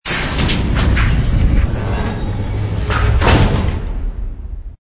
door_medium_close.wav